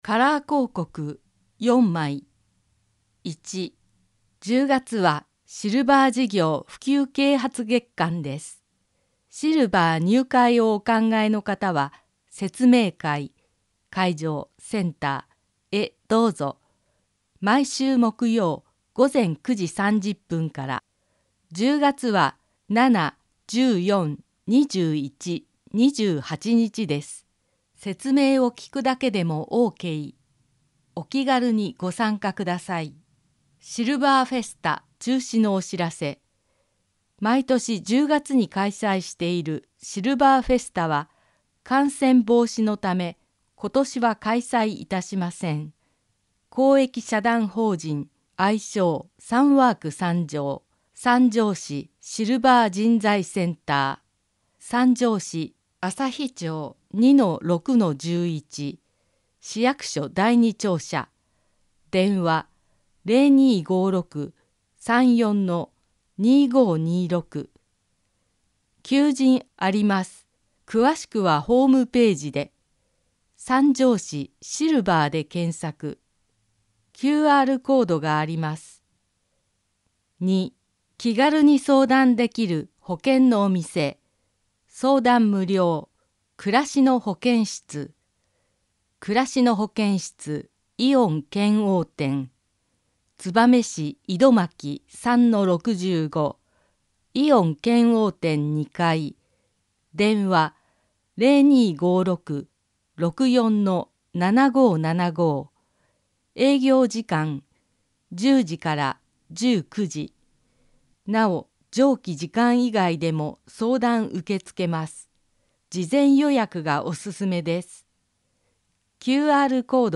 声の広報さんじょう令和3年10月1日号